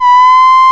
VOICE C5 S.wav